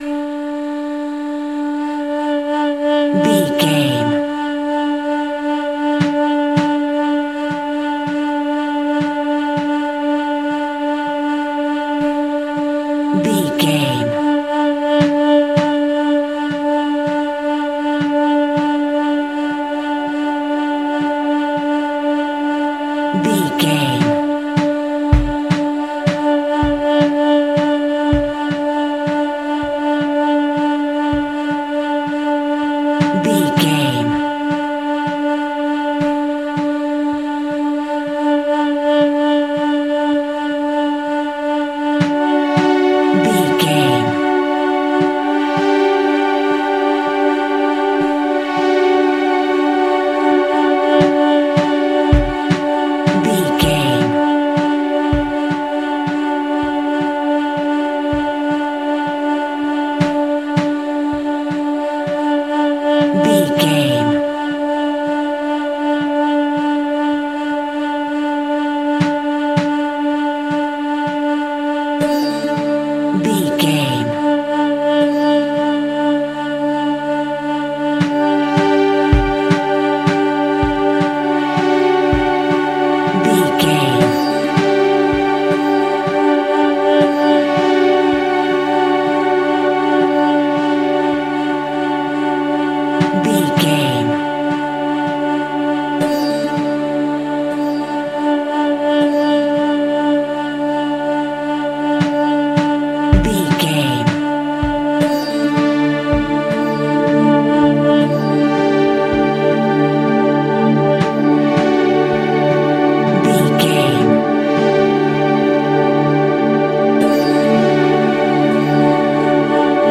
A stunning mystical eastern ambience.
Atonal
Slow
World Music
ethnic percussion
synth lead
synth pad